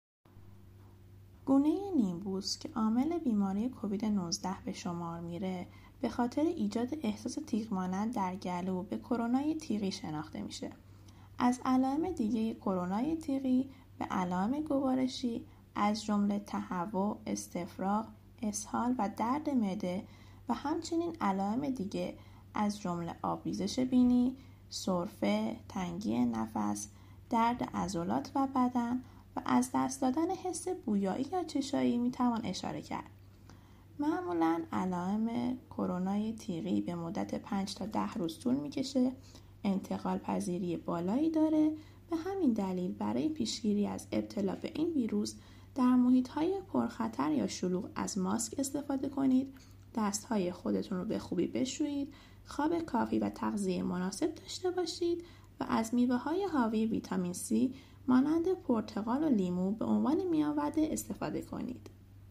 برای دریافت توضیح تکمیلی از نگاه تخصصی، صوت ارائه‌شده توسط پزشک را گوش کنید: